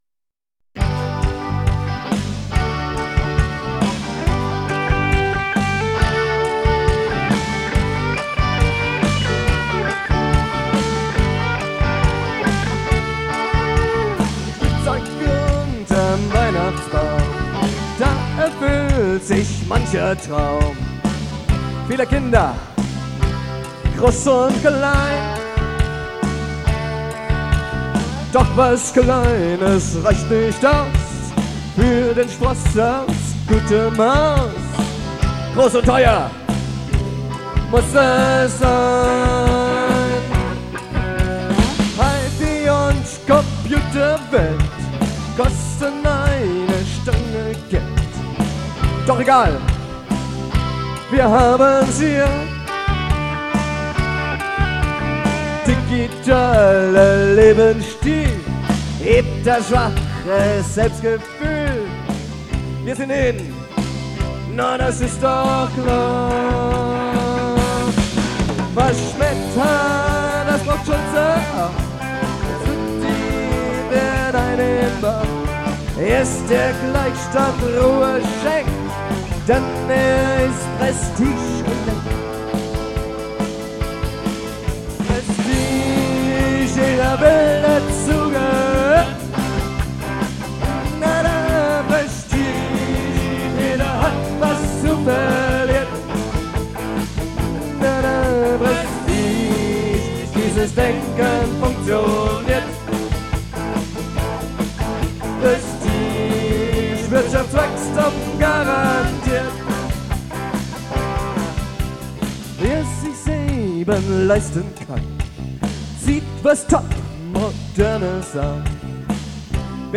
Audio (live; 6:09)Herunterladen